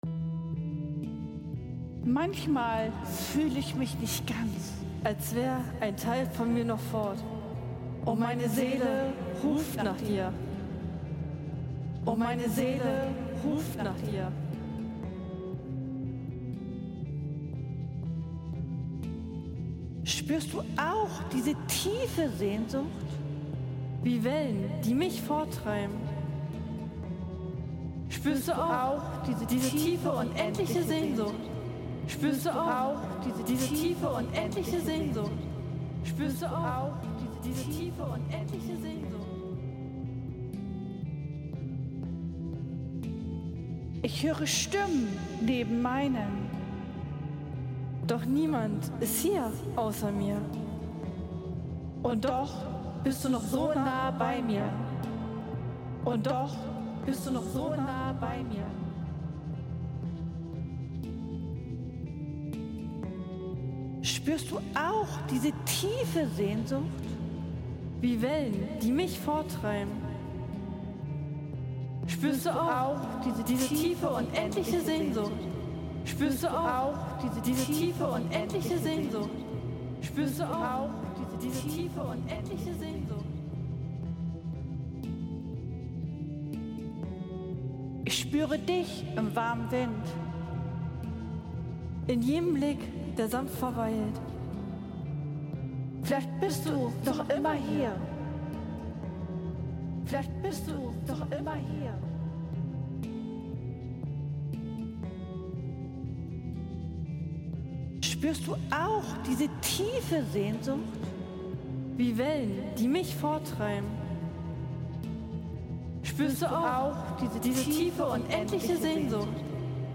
Dieses Lied